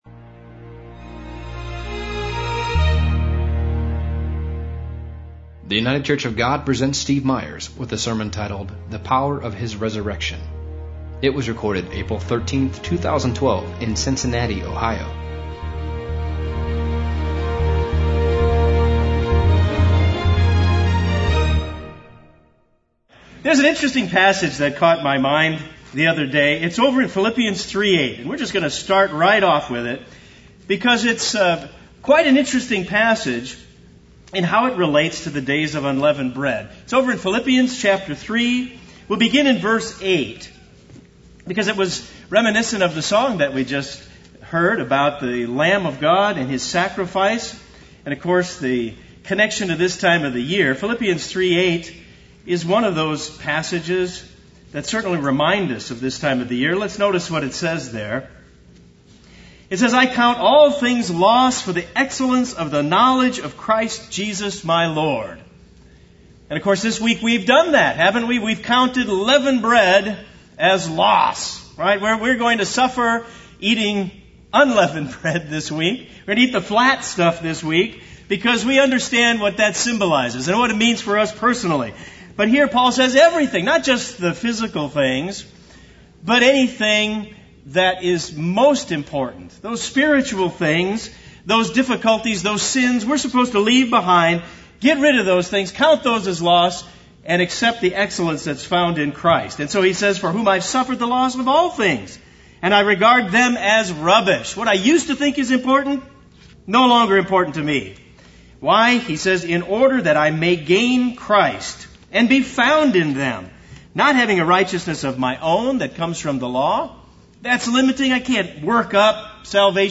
[ This sermon was given on the last day during the Days of Unleavened Bread. ]